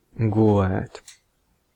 Ääntäminen
Vaihtoehtoiset kirjoitusmuodot (vanhentunut) lye Synonyymit bullshit deception falsehood fib prevarication leasing rest untruth gloss liement Ääntäminen US : IPA : [ˈlɑɪ] Tuntematon aksentti: IPA : /laɪ̯/